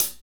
HAT R B CH0E.wav